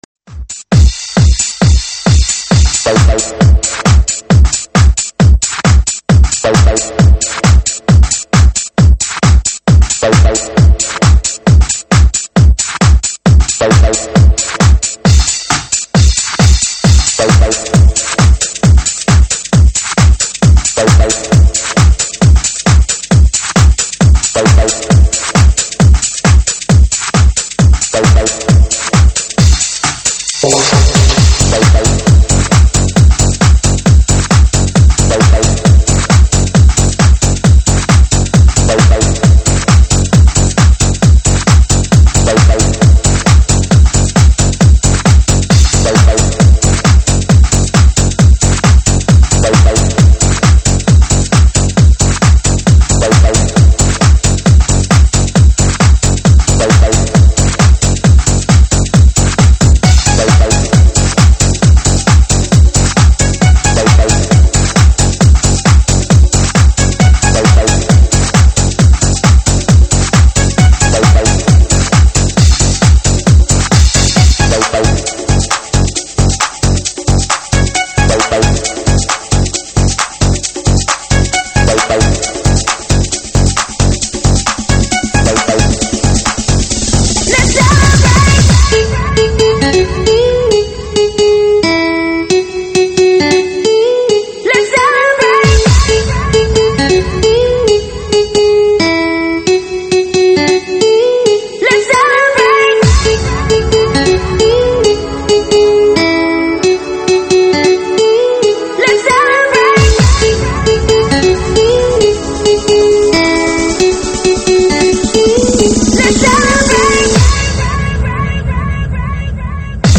音乐与您相会在这里，空旷寂寥的声音唤醒沉睡的大地。